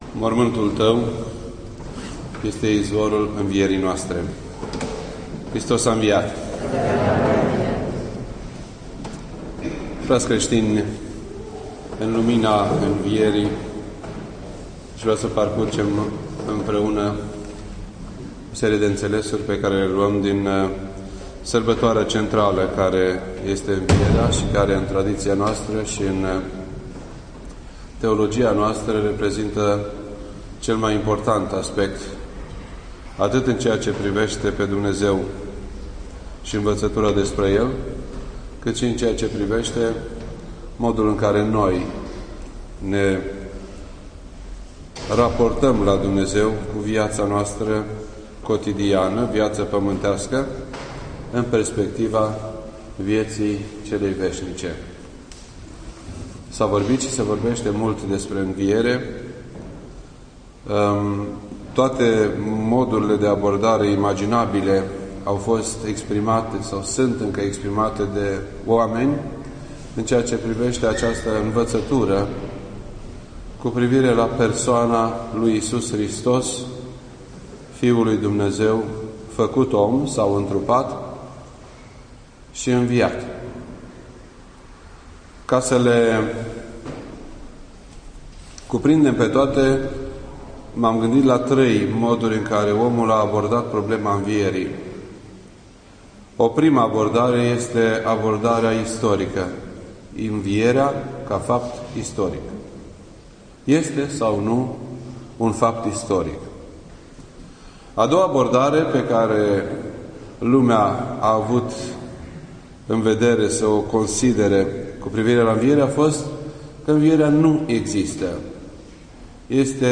This entry was posted on Monday, April 16th, 2012 at 8:47 PM and is filed under Predici ortodoxe in format audio.